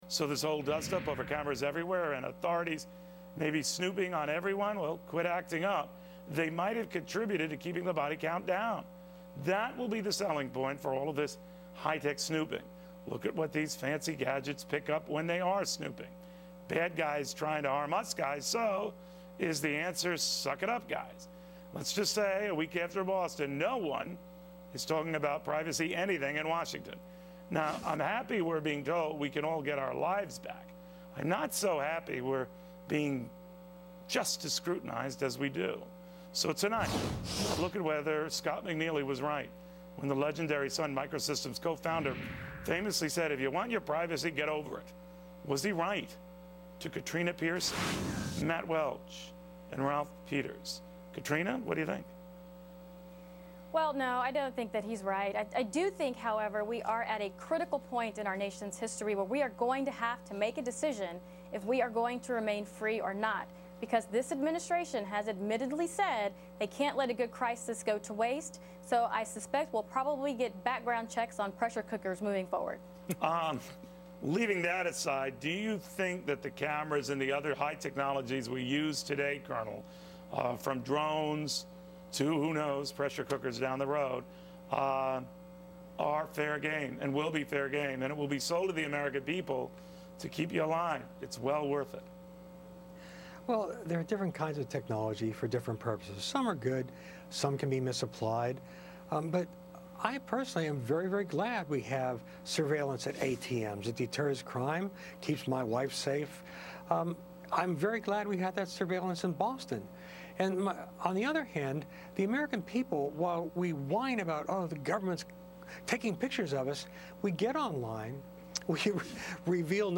appeared on Fox Business's Cavuto to discuss the Boston Marathon bombing and how government should react to domestic terrorism when surveillance threatens privacy laws